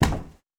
Padding on Glass Distant Hit.wav